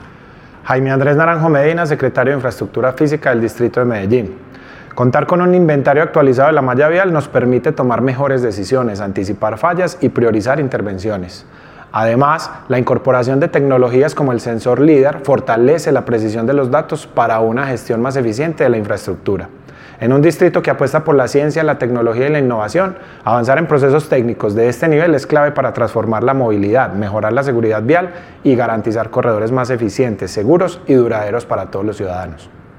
Declaraciones del secretario de Infraestructura Física, Jaime Andrés Naranjo Medina
Declaraciones-del-secretario-de-Infraestructura-Fisica-Jaime-Andres-Naranjo-Medina.mp3